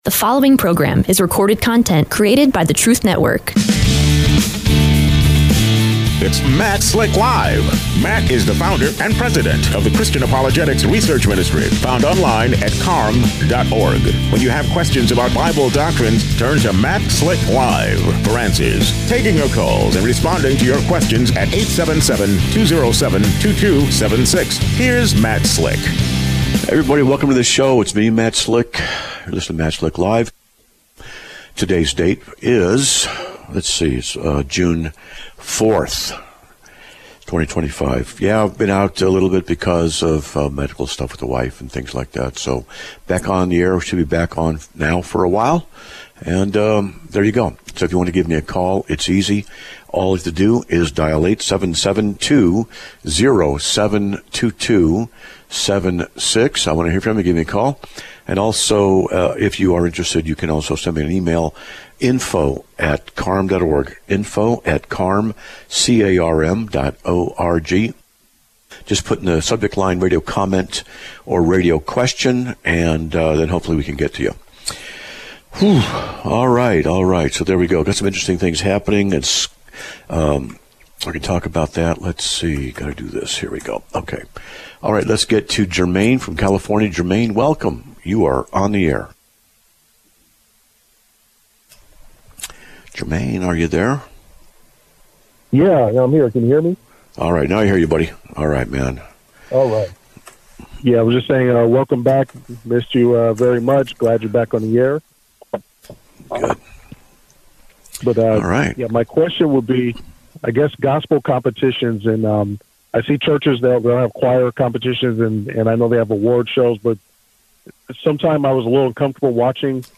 Answers will be discussed in a future show.